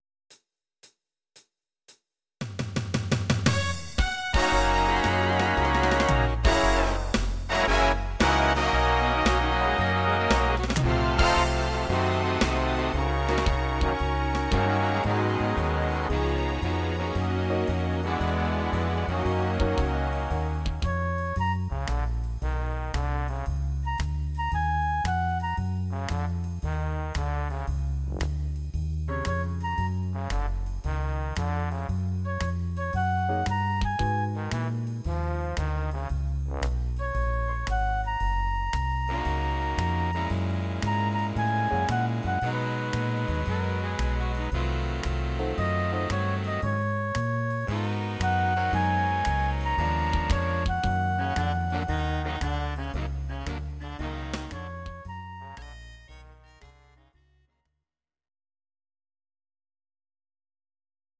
These are MP3 versions of our MIDI file catalogue.
Please note: no vocals and no karaoke included.
Your-Mix: Jazz/Big Band (731)